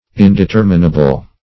Indeterminable \In`de*ter"mi*na*ble\, a. [L. indeterminabilis: